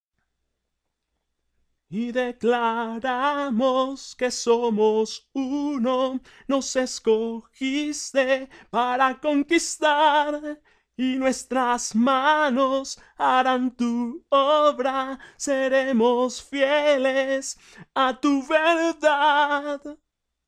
Voz adicional Coro (Hombre )